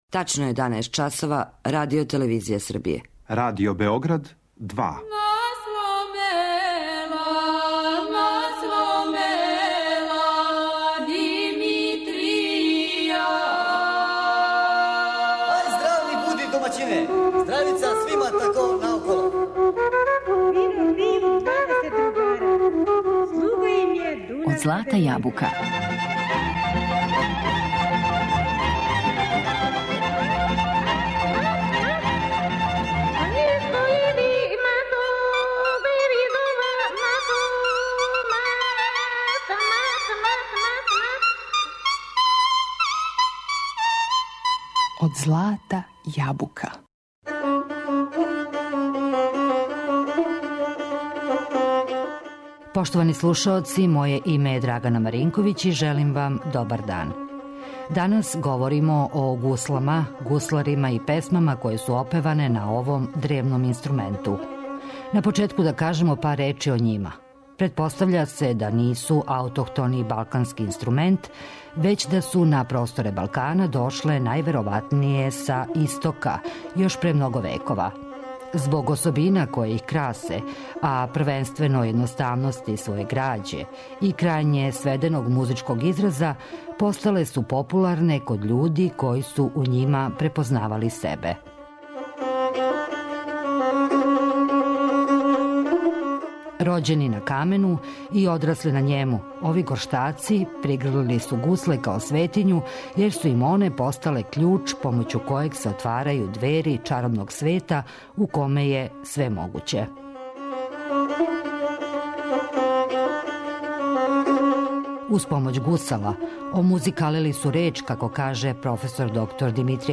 Емисија је резервисана за изворну народну музику.